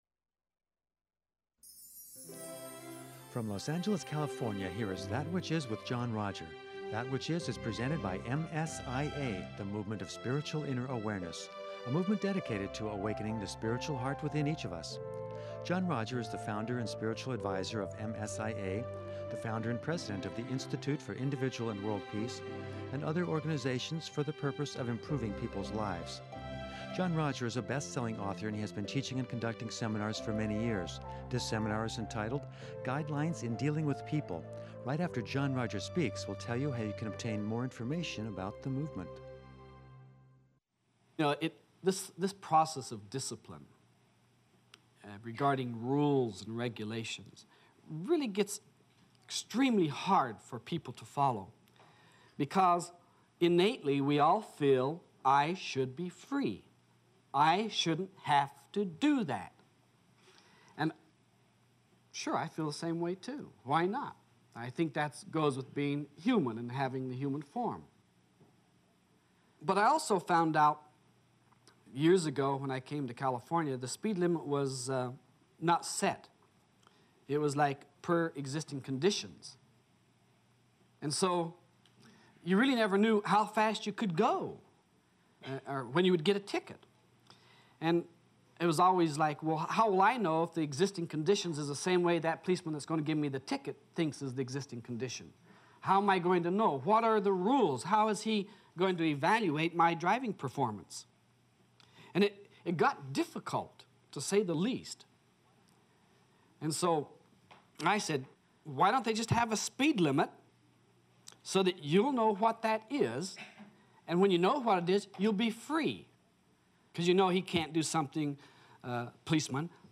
In part one of this helpful seminar